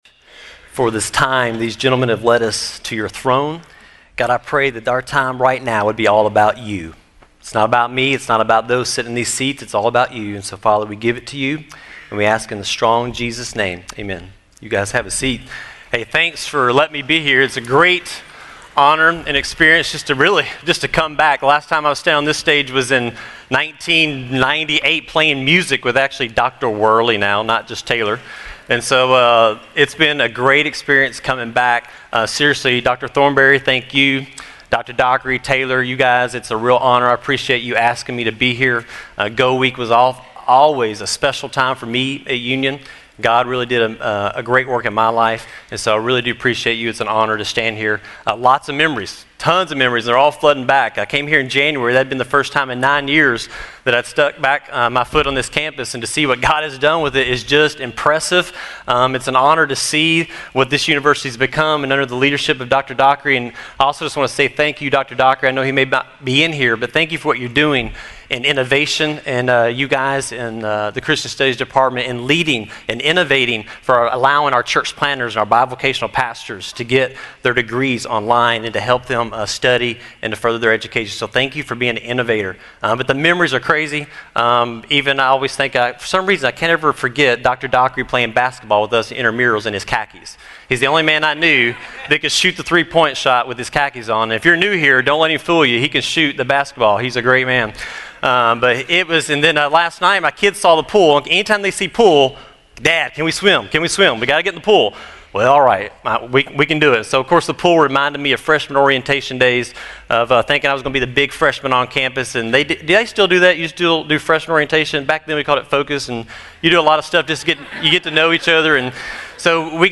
GO Week Chapel